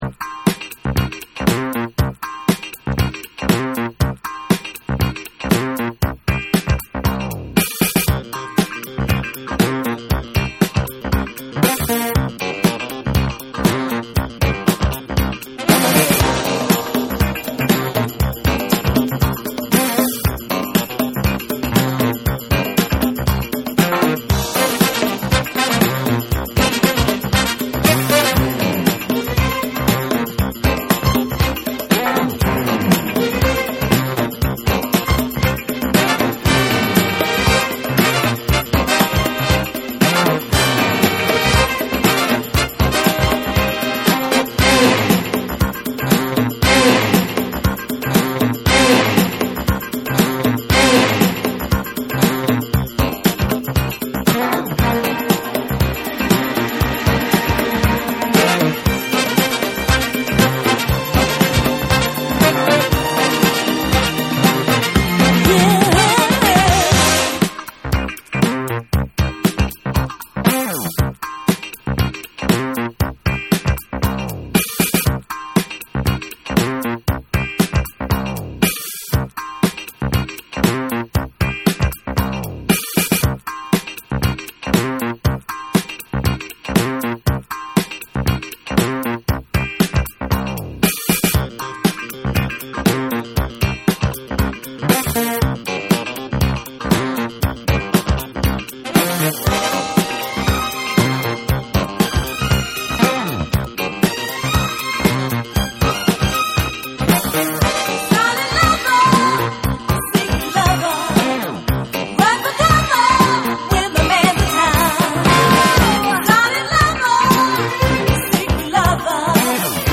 エレクトリックなファンキー・ディスコ
DANCE CLASSICS / DISCO / RE-EDIT / MASH UP